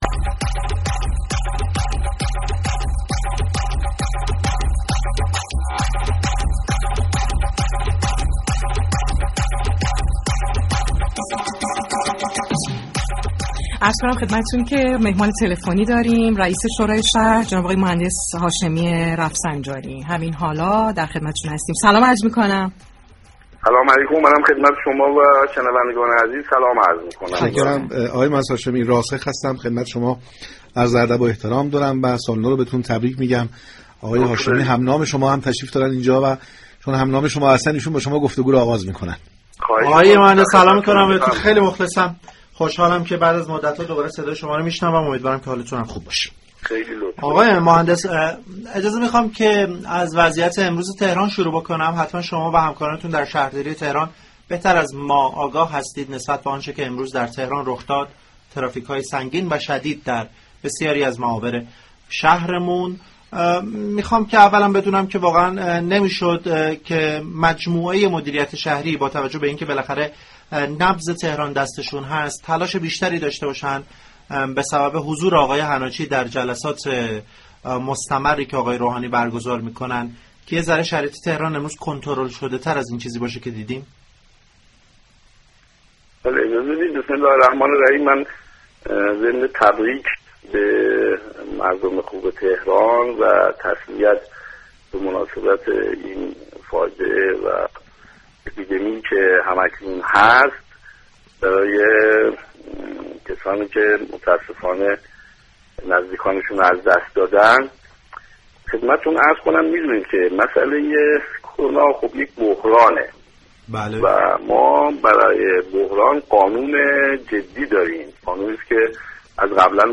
محسن هاشمی، رئیس شورای اسلامی شهر تهران در گفتگو با برنامه پارك شهر 16 فروردین از تصمیمات رئیس جمهور در زمینه مدیریت بحران كرونا انتقاد كرد.